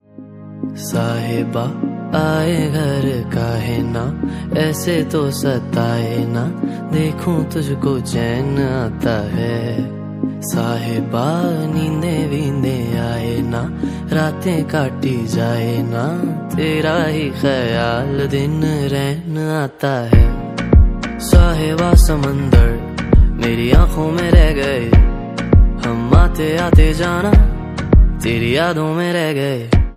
Love Song Ringtone